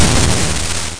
1 channel
Crush.mp3